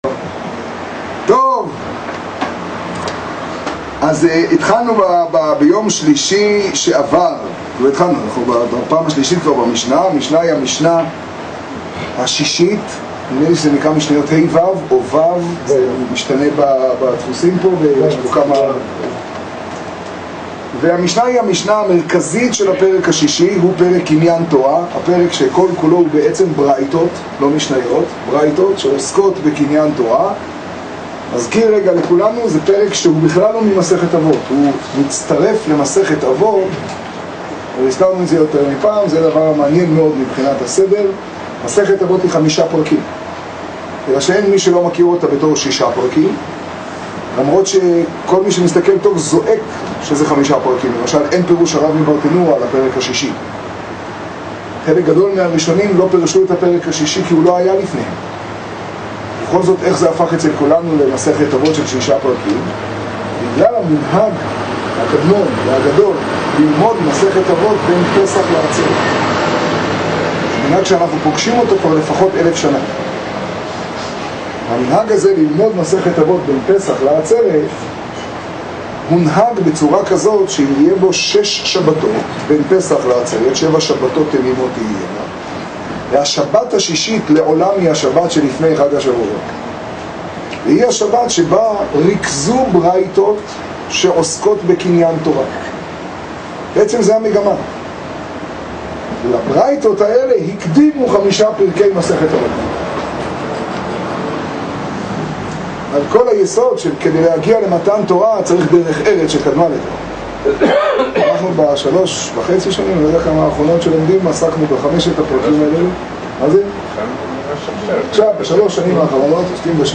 השיעור בחצור תשעג.
קטגוריה: שיעור, שיעור בחצור, תוכןתג: פרקי אבות ו, תשעג